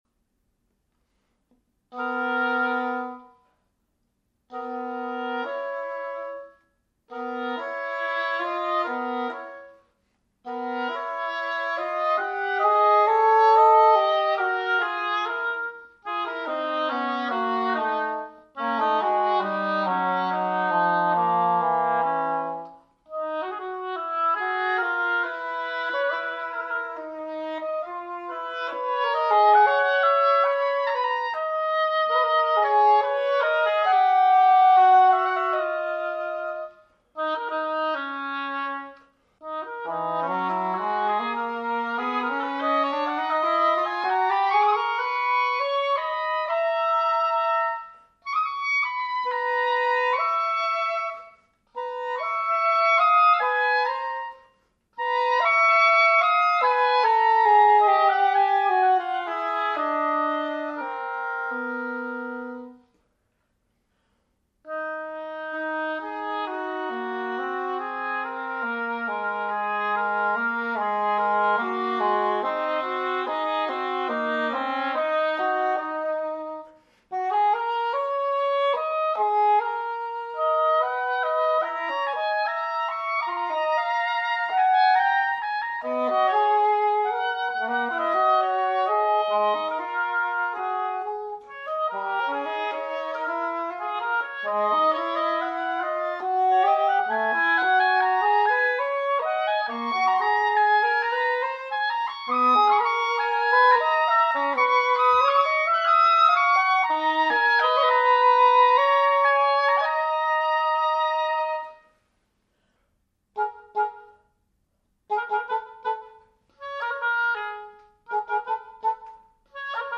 for Oboe and English Horn